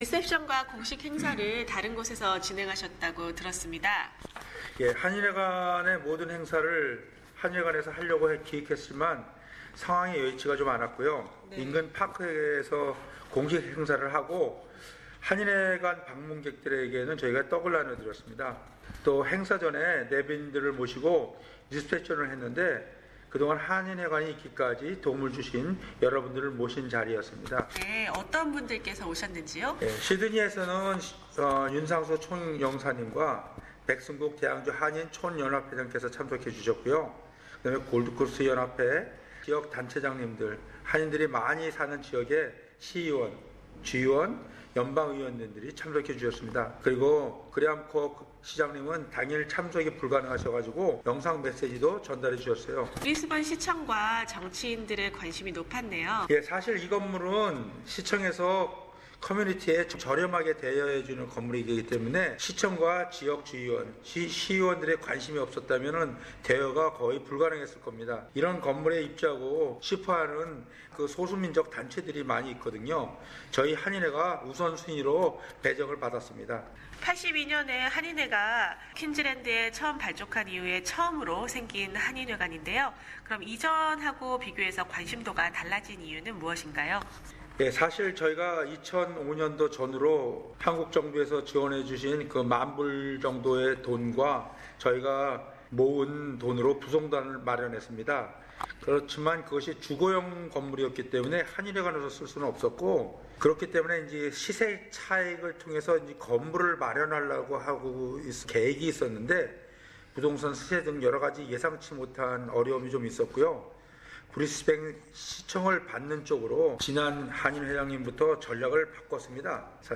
Full interview is available on the audio news.